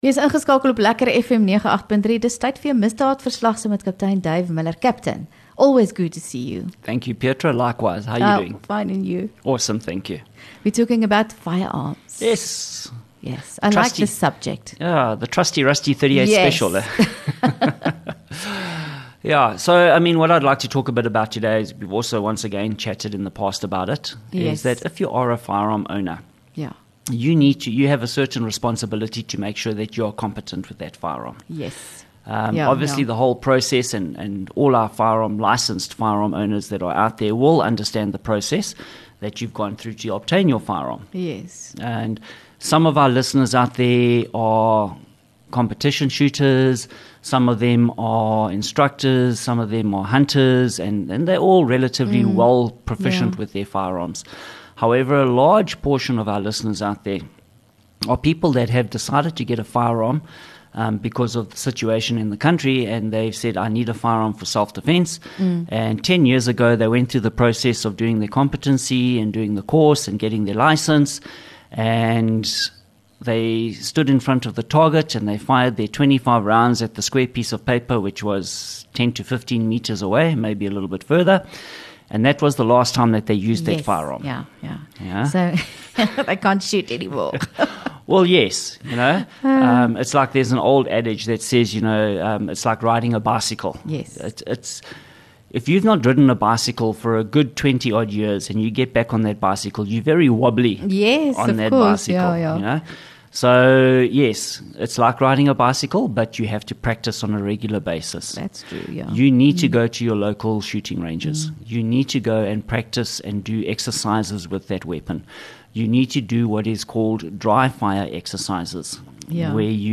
LEKKER FM | Onderhoude 23 Jul Misdaadverslag